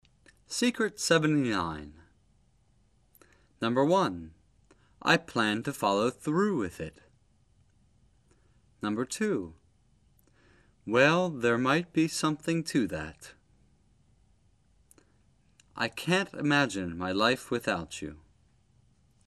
秘诀79    咬舌摩擦音的发音技巧
英音=美音［W  T］